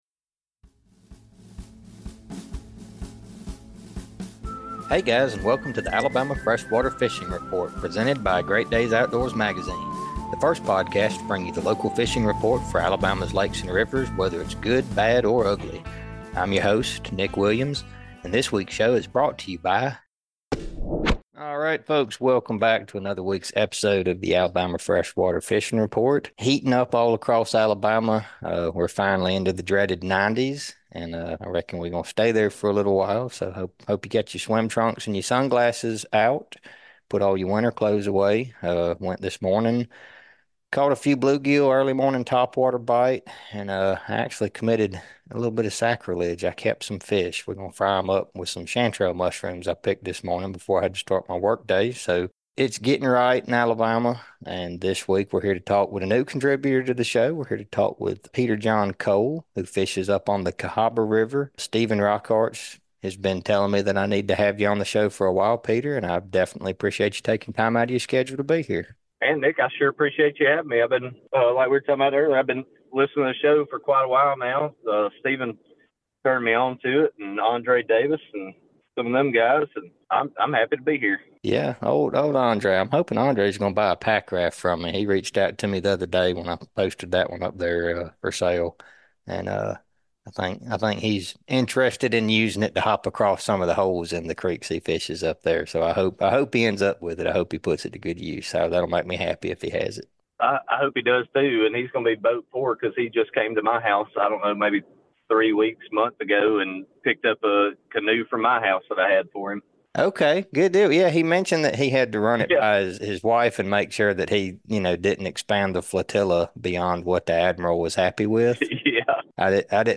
In celebration of these otherworldly-looking flowers, we’re sitting down with two guests this week that live in the heart of Cahaba Lily country.